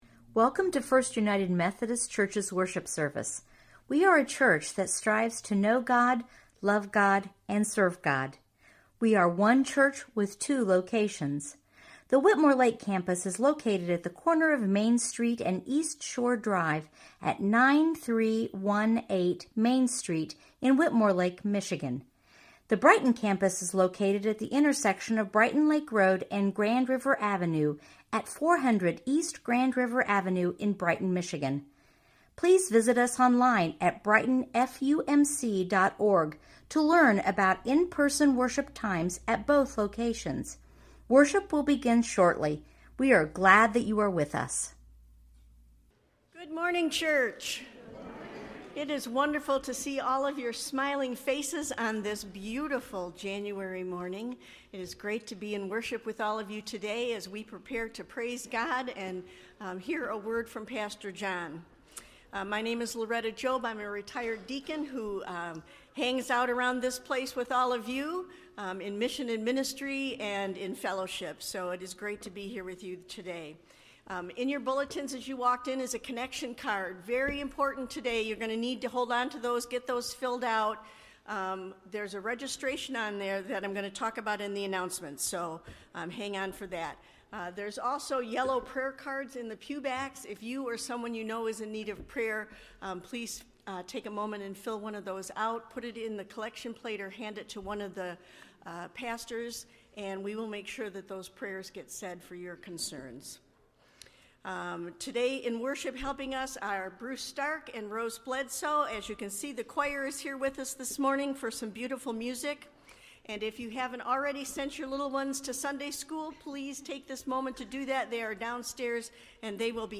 Sermons recorded live at Brighton First United Methodist Church in Brighton, Michigan.